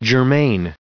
370_germane.ogg